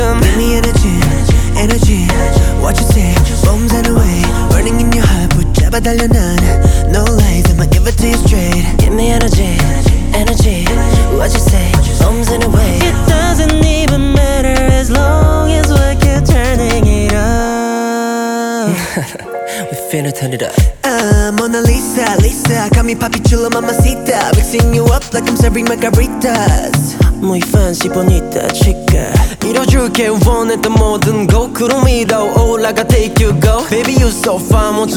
K-Pop
2025-06-13 Жанр: Поп музыка Длительность